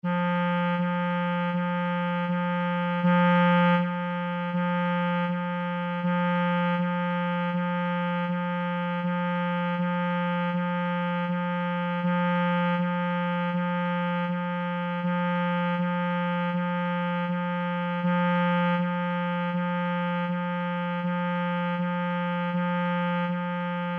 NOTAS MUSICAIS
NOTA FA